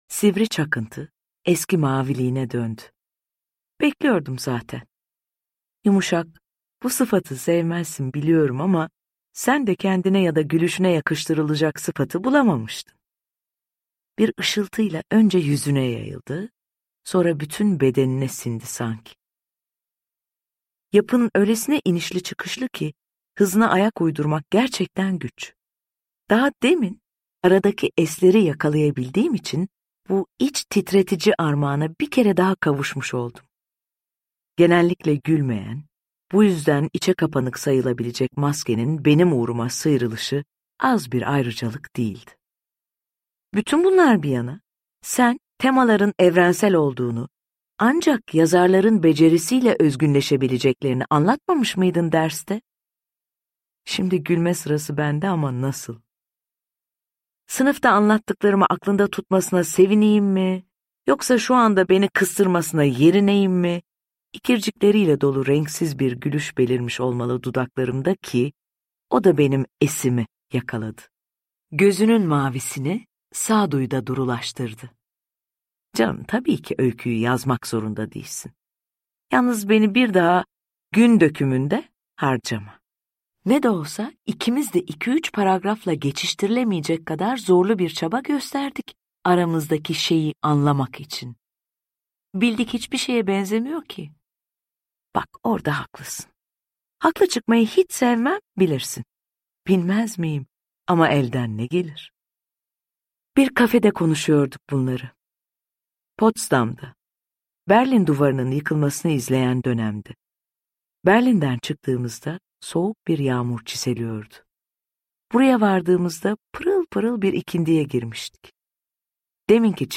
Aramızdaki Şey’i Tilbe Saran’ın seslendirmesiyle dinleyebilirsiniz.